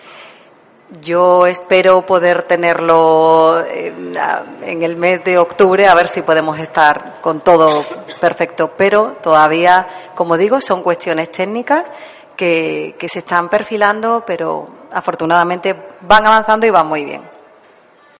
Rocío Díaz, consejera de Fomento de la Junta de Andalucía